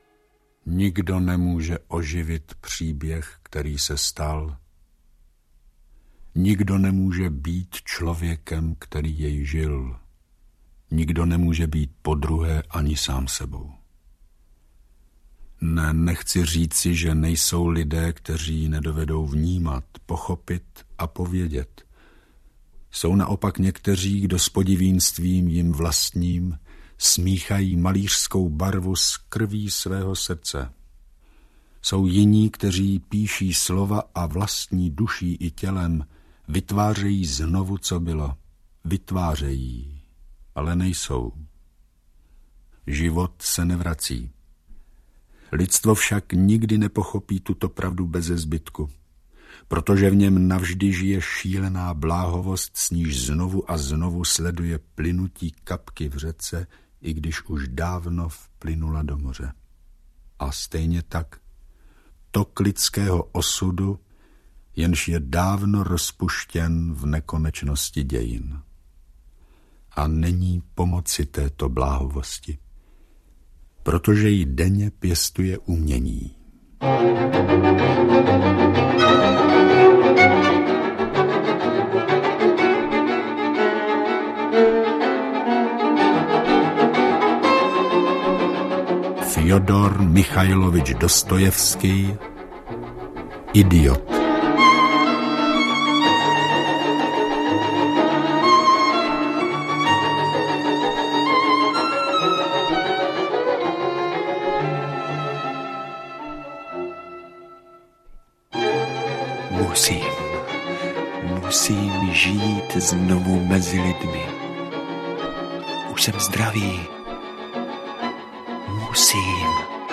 Audiokniha Idiot - rozhlasová inscenace proslulého románu, který napsal Fjodor Michajlovič Dostojevský.